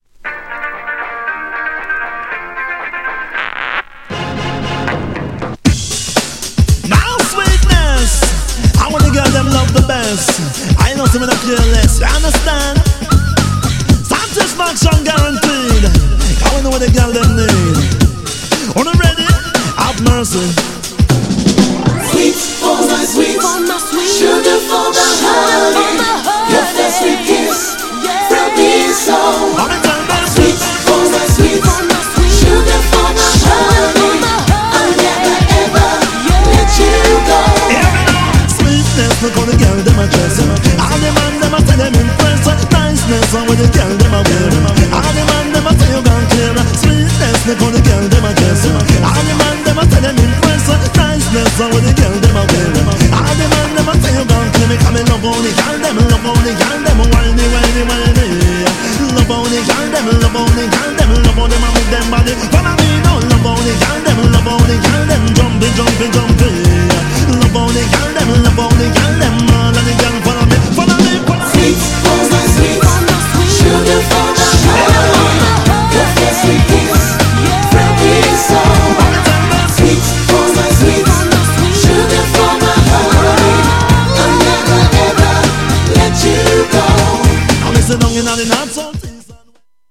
GENRE R&B
BPM 111〜115BPM
# 90sポップ
# ちょいハネ系 # アゲアゲ # レゲエ # レゲエテイスト # 男性VOCAL_R&B